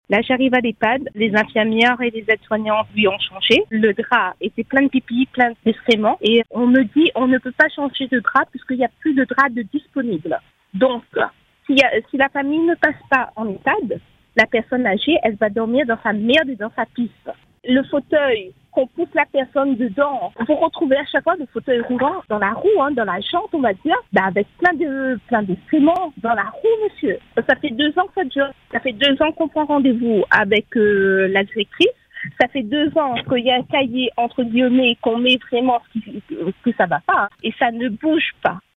Hier matin, une auditrice a livré un témoignage bouleversant sur l’antenne de Radio Free Dom.